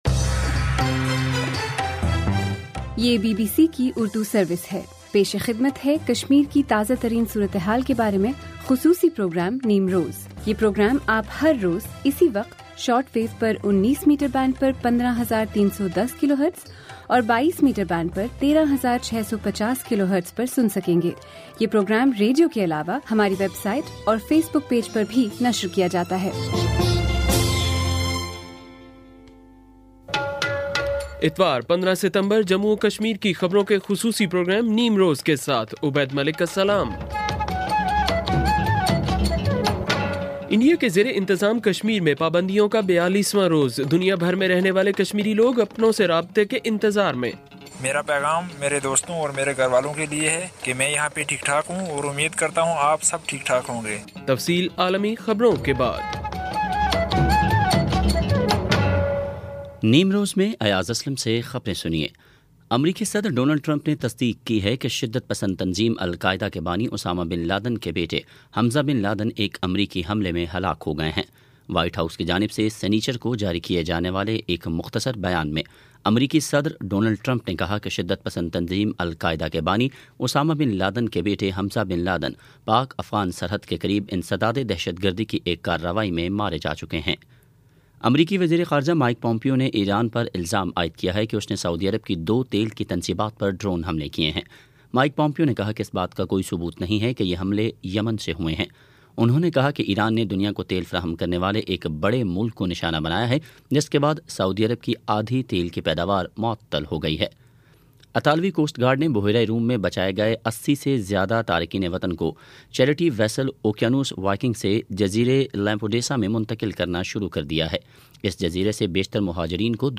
بی بی سی اردو سروس سے جموں اور کشمیر کی خبروں کا خصوصی پروگرام نیم روز